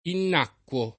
innacquo [ inn # kk U o ]